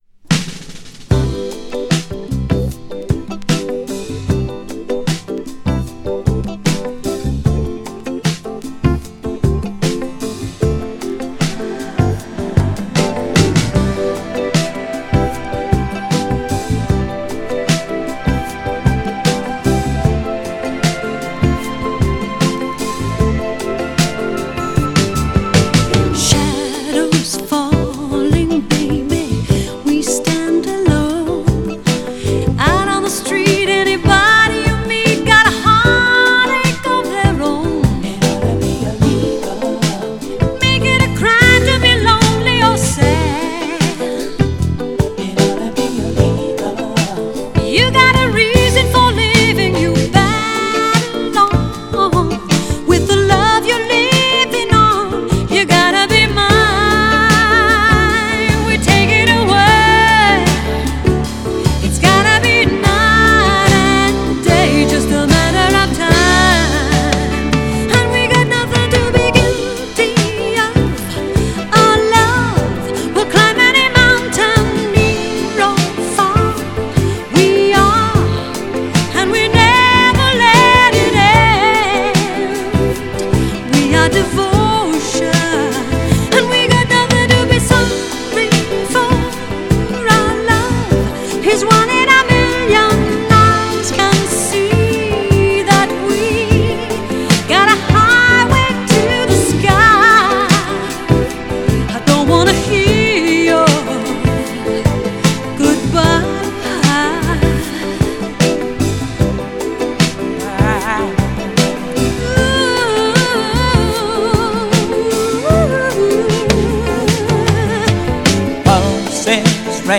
当時の恋人同士でデュエットした俳優二人による愛のバラード。
GENRE Dance Classic
BPM 51〜55BPM
# AOR
# POP # エモーショナル # スロー # ドラマティック # メロウ